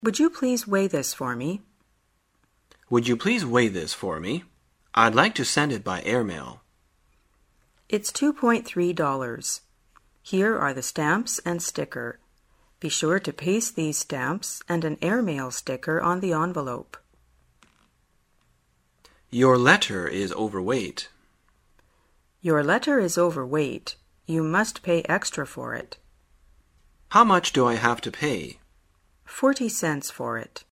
旅游口语情景对话 第241天:如何谈论邮件重量